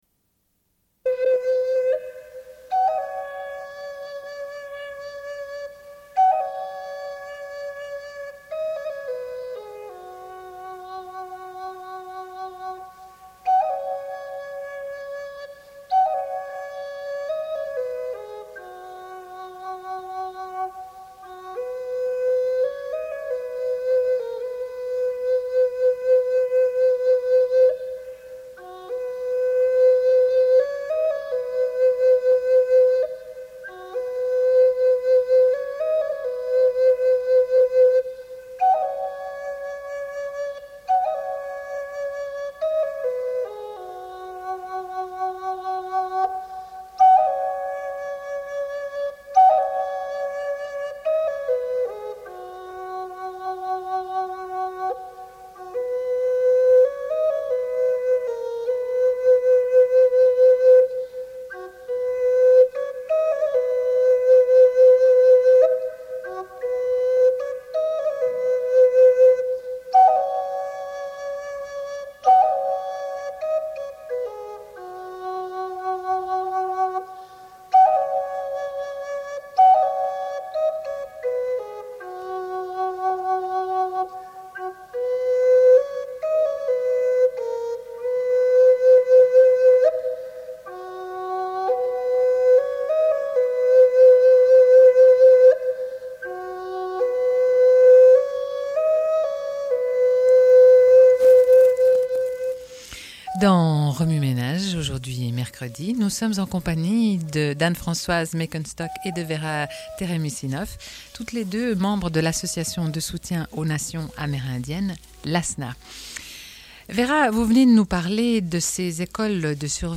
Une cassette audio, face B00:28:53